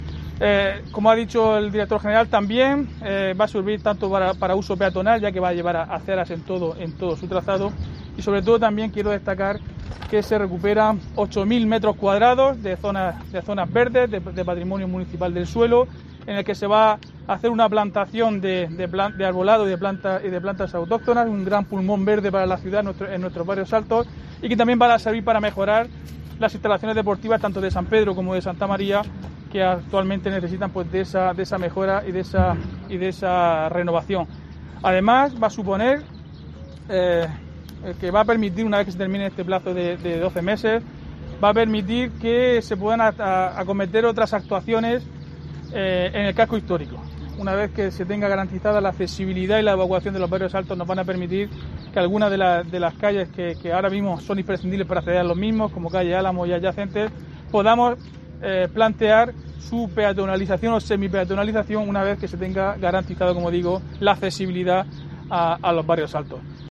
Diego José Mateos, alcalde de Lorca